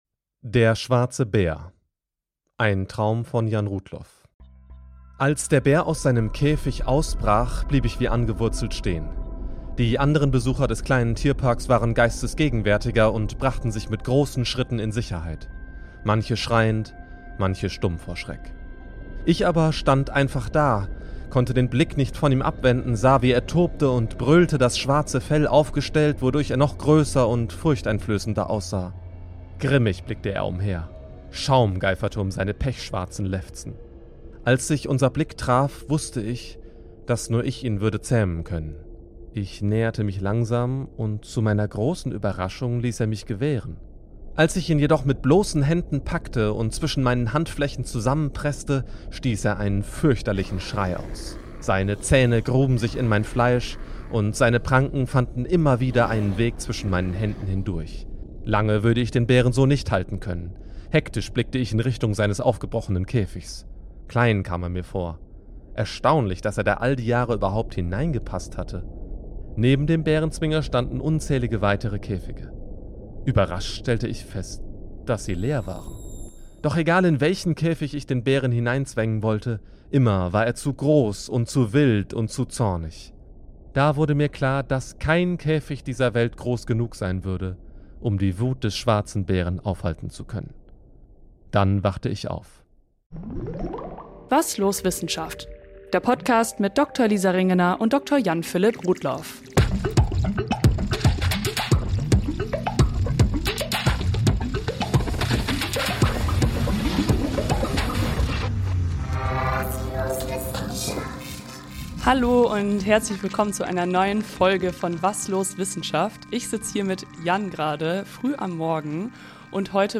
Ein Gespräch über das Unbewusste, schlaflose Nächte und die faszinierendsten Ecken unseres Gehirns.